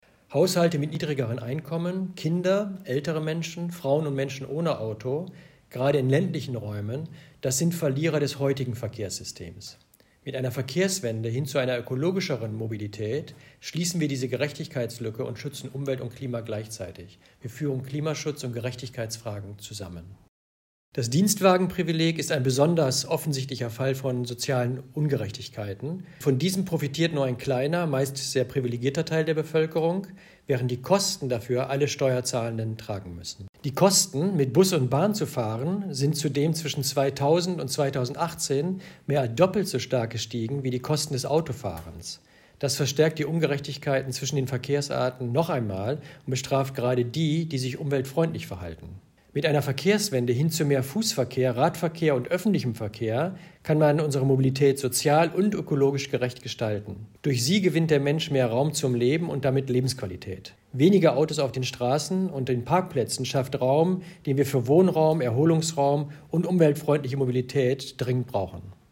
UBA-Präsident Dirk Messner zur Verkehrswende für ALLE